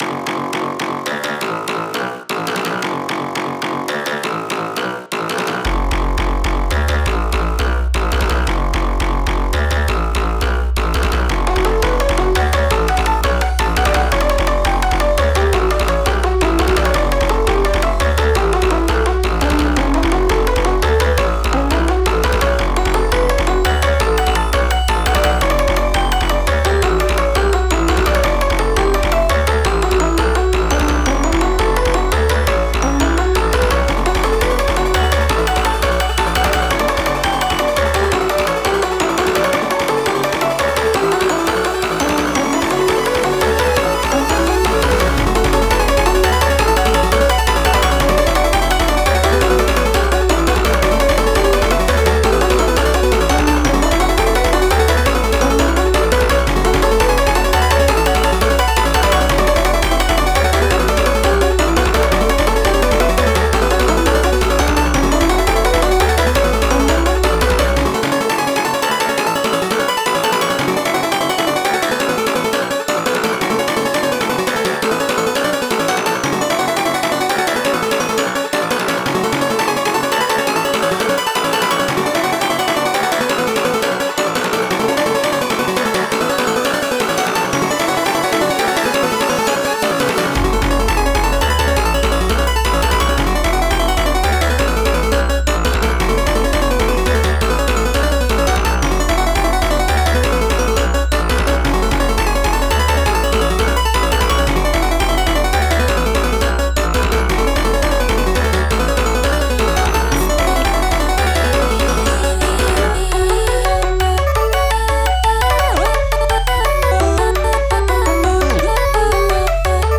breakcore, glitch, electronica,